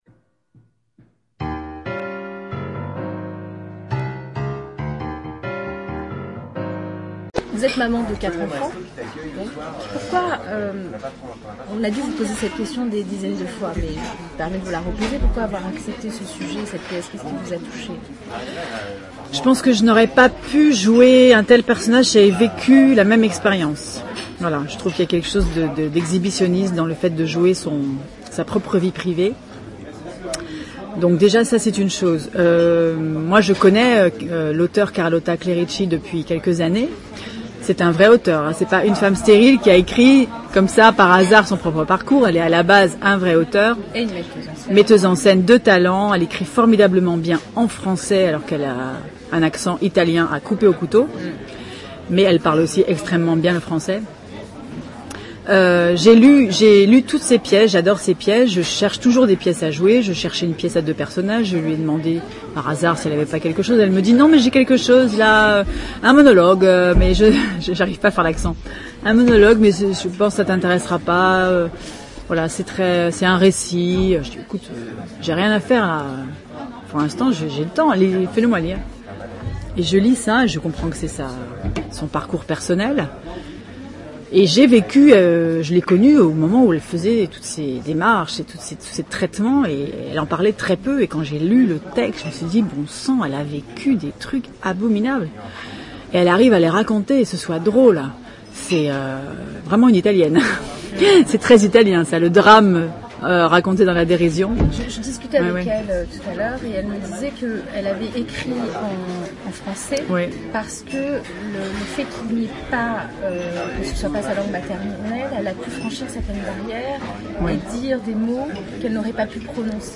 Catherine Marchal, interview, épisode 2.mp3 (4.24 Mo)